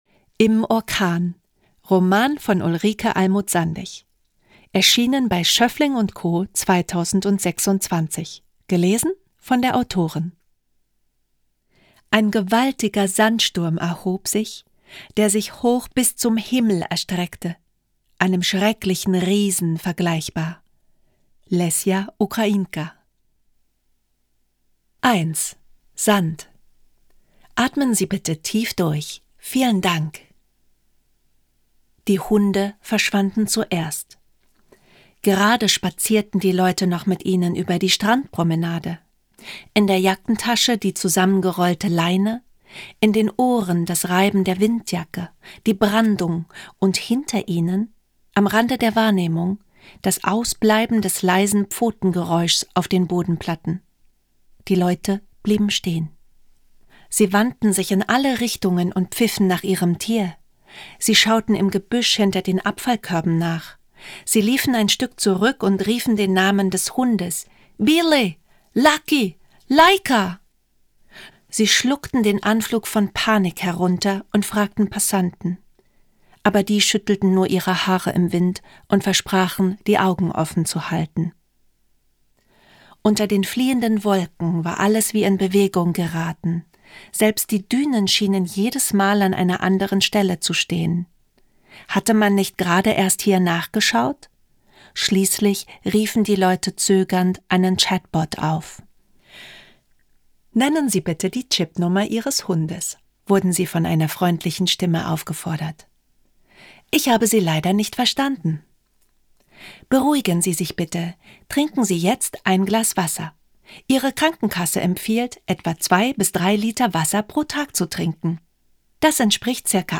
»Im Orkan« gibt es jetzt als Hörbuch, gelesen von Ulrike Almut Sandig | Ulrike Almut Sandig